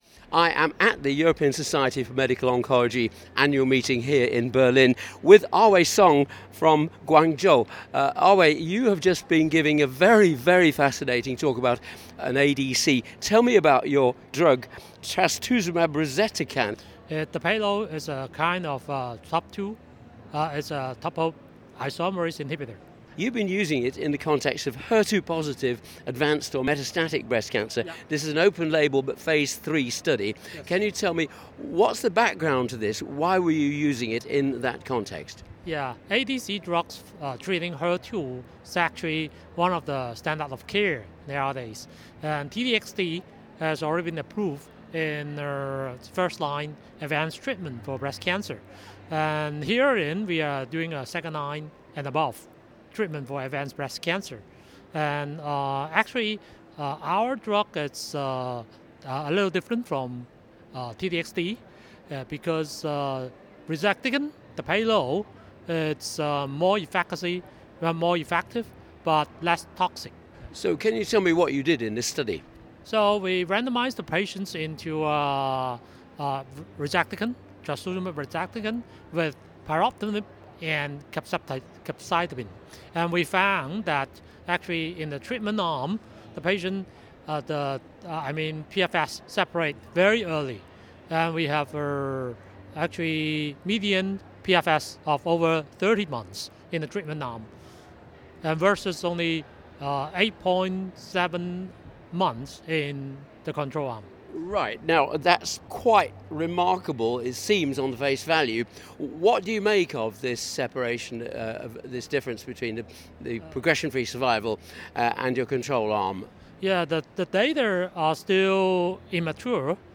Interviews with the world’s key opinion leaders in cancer
An interview with: